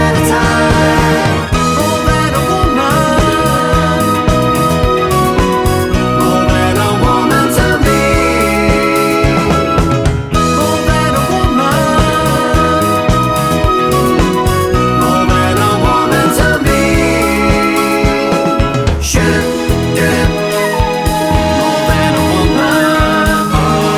no Backing Vocals Disco 3:36 Buy £1.50